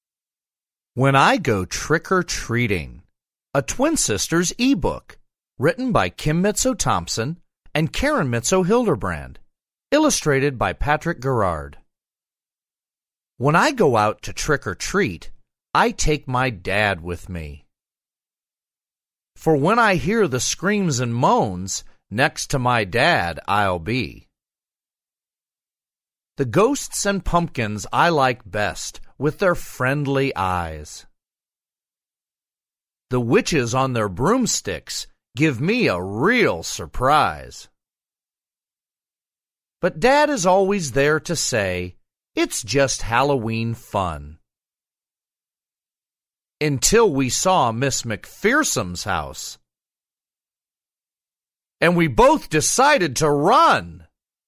Reading When I Go Trick-Or-Treating